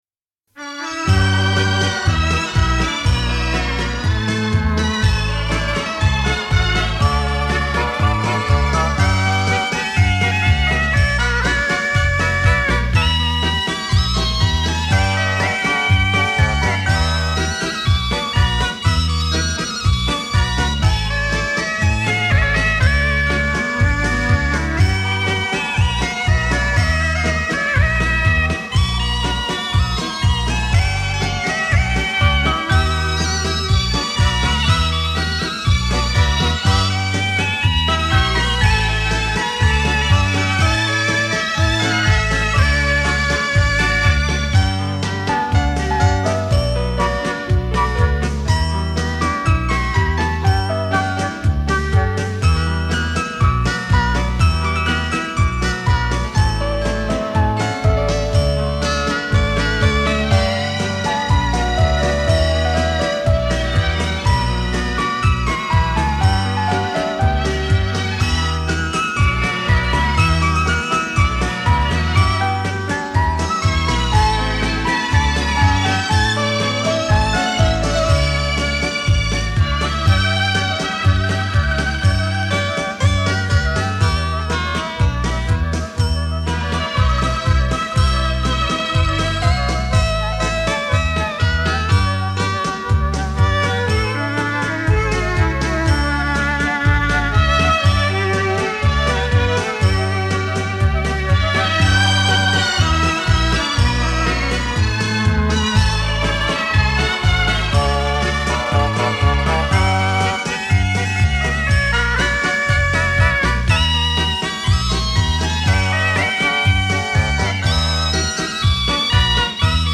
伦巴